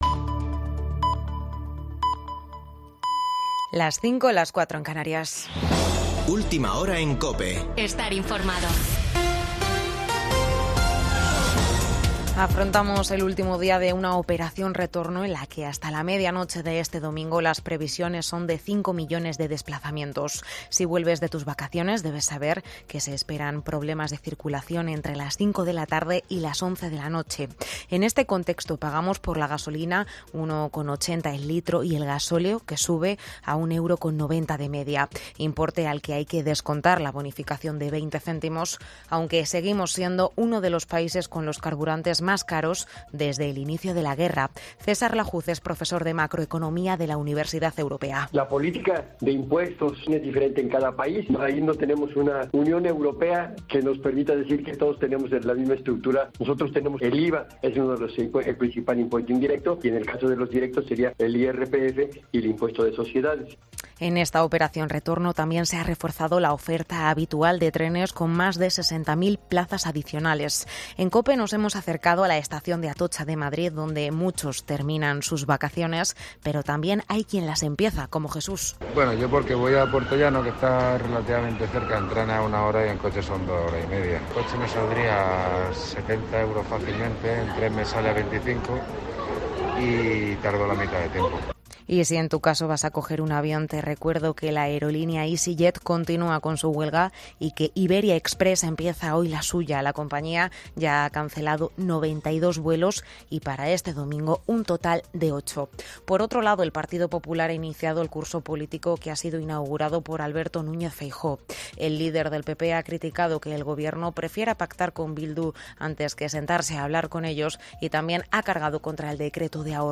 Boletín de noticias de COPE del 28 de agosto de 2022 a las 05.00 horas